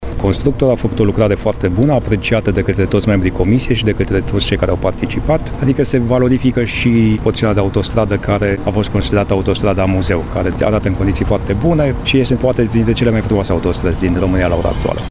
Narcis Neaga, directorul Companiei Naţionale de Administrare a Infrastructurii Rutiere: